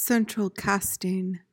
PRONUNCIATION:
(sen-truhl KAS-ting)